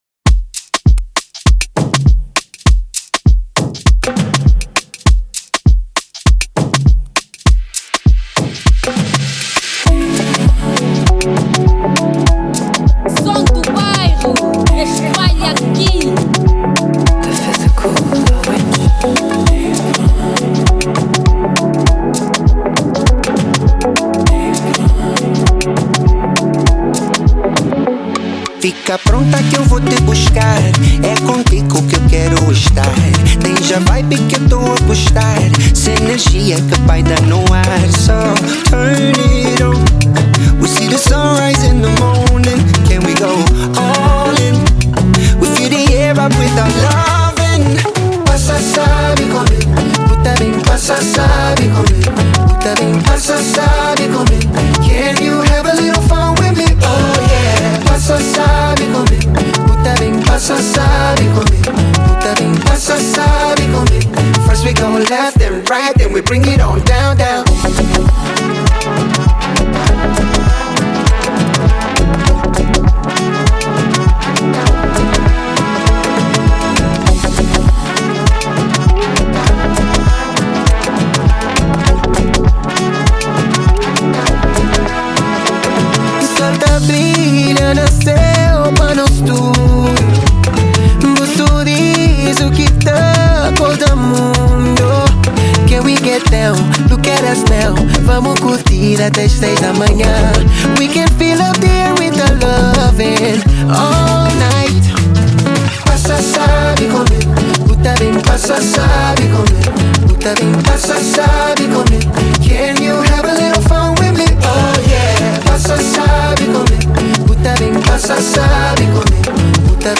Genero: Afro Beat